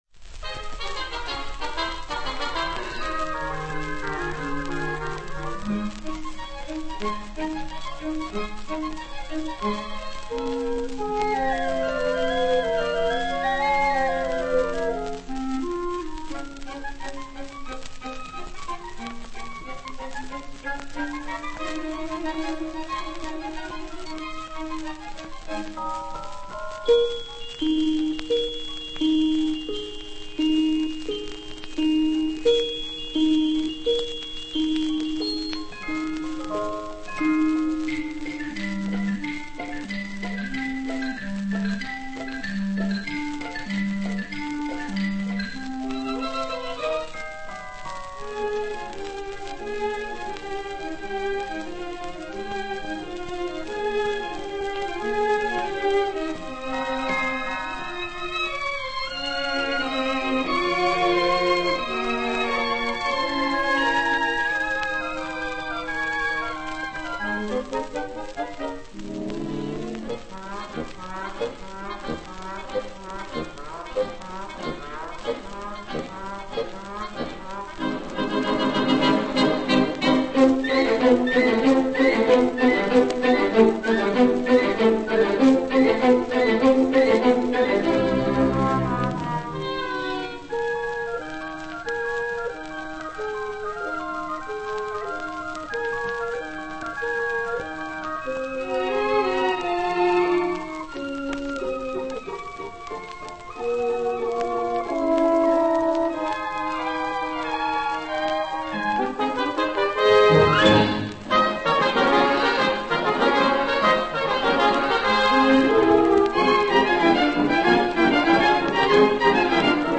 Пластинка 40-х гг.